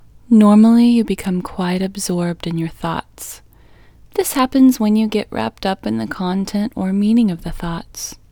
LOCATE IN English Female 7